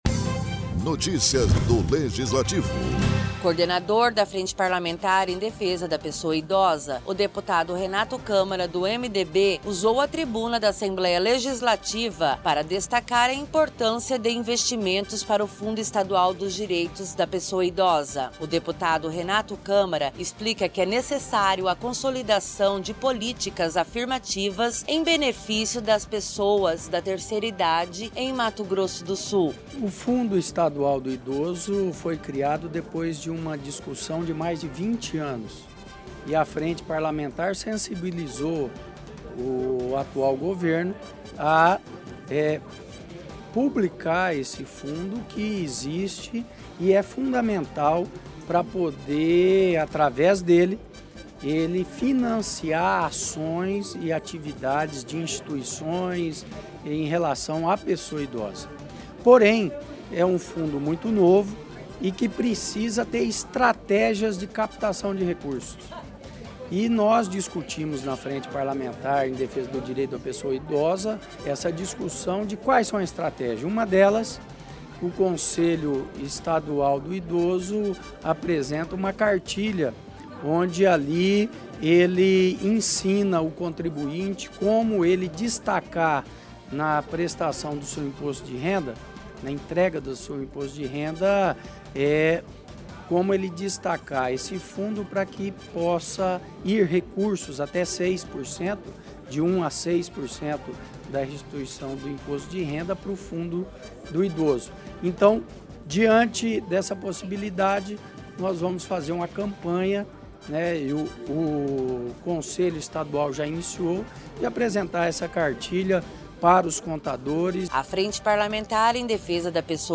O deputado estadual Renato Câmara, do MDB usou a tribuna, para comentar as ações desempenhadas pela Frente Parlamentar em Defesa dos Direitos da Pessoa Idosa, e a consolidação de políticas afirmativas em benefícios das pessoas da terceira idade em Mato Grosso do Sul.